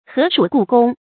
禾黍故宫 hé shǔ gù gōng
禾黍故宫发音